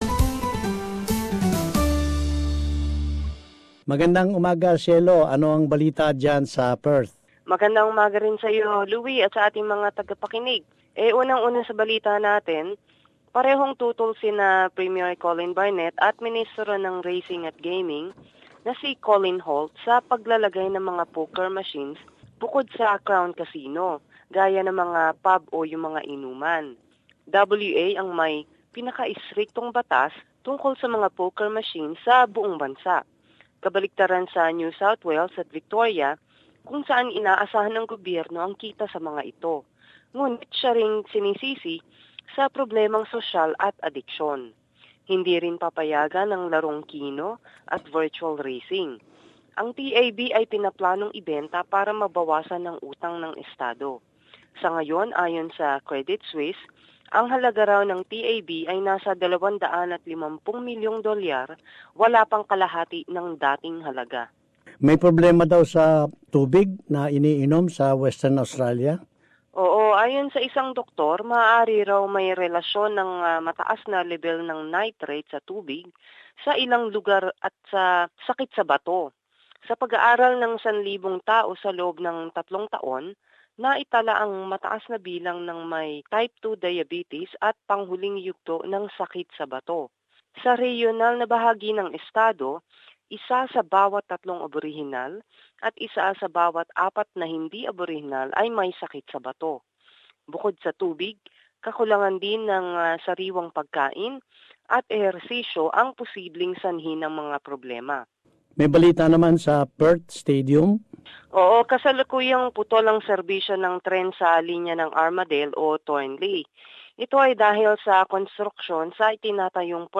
Perth Report. Summary of latest news from the state